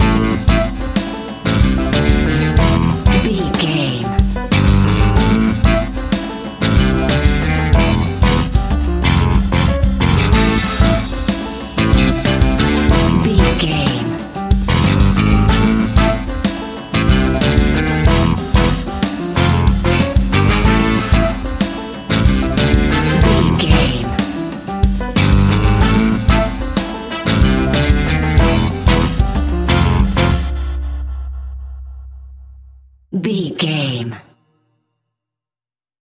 Aeolian/Minor
flamenco
latin
uptempo
brass
saxophone
trumpet
fender rhodes